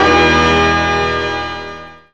JAZZ STAB 1.wav